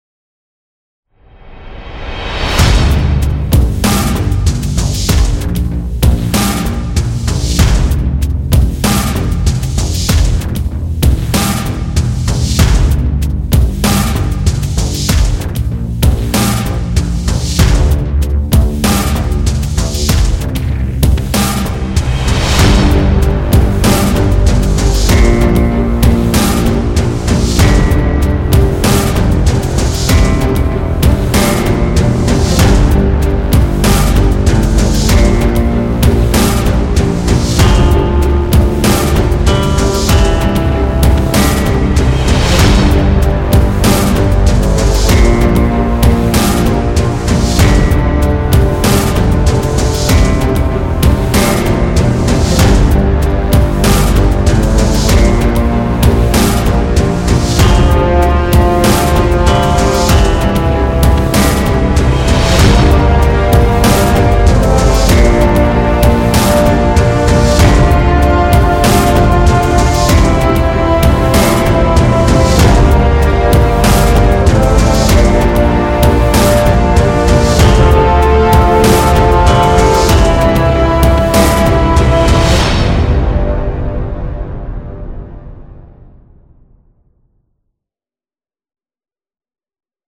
描述：只是stinger用于介绍使用
标签： 管弦乐 标志 毒刺 拖车 介绍 主题 电影 戏剧 电影 英雄
声道立体声